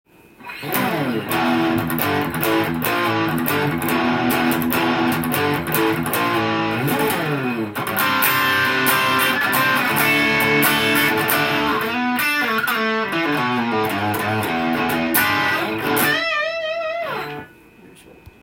試しに弾いてみました
音の方は、ハードロックやヘビメタが出来そうな
良好なギターサウンドでした！